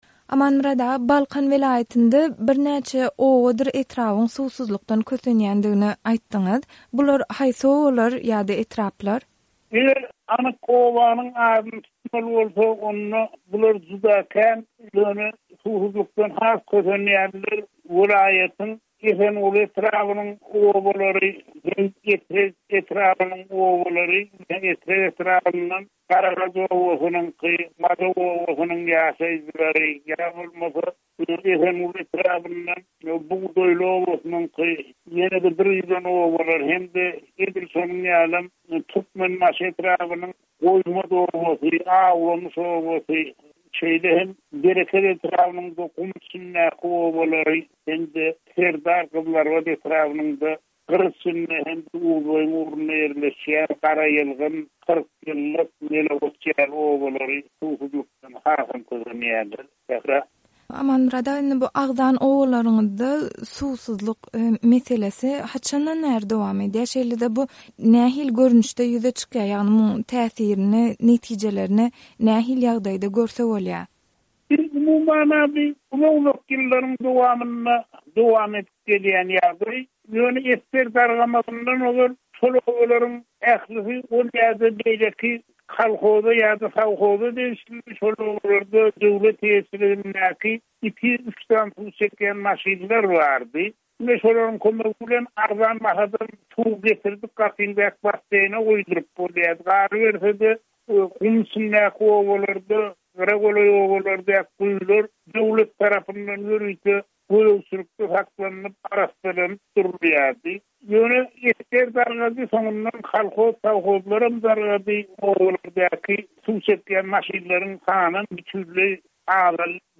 söhbetdeş boldy.